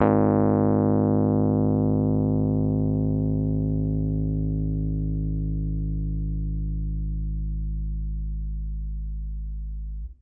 piano-sounds-dev
Rhodes_MK1